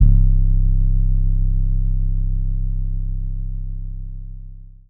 Gameboy 808.wav